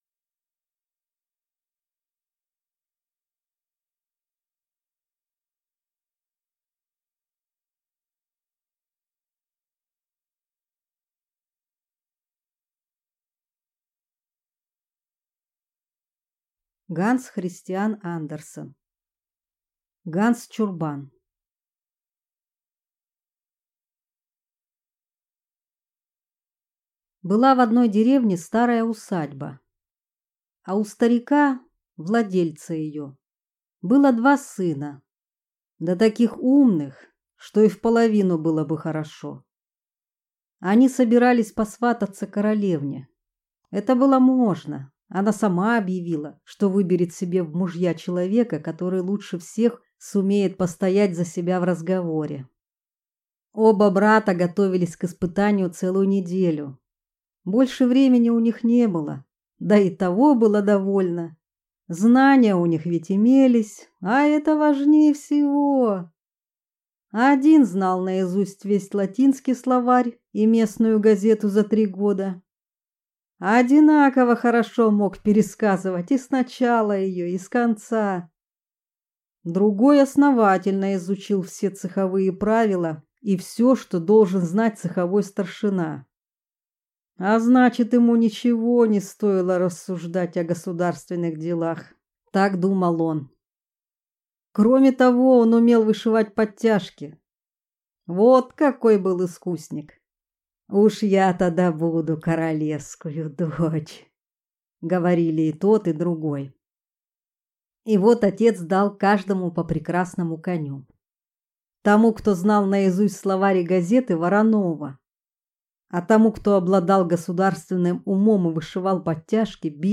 Аудиокнига Ганс Чурбан | Библиотека аудиокниг